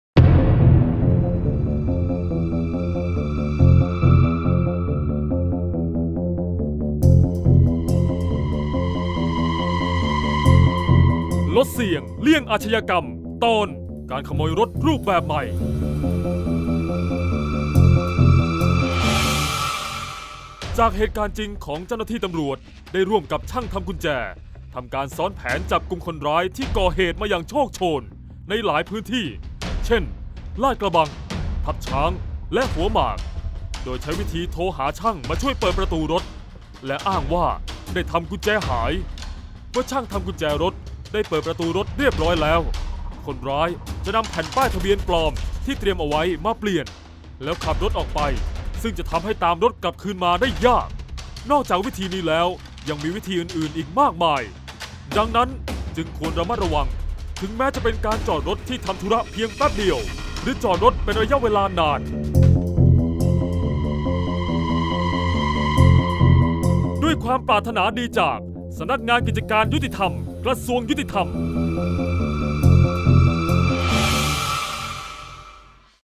เสียงบรรยาย ลดเสี่ยงเลี่ยงอาชญากรรม 15-ขโมยรถแบบใหม่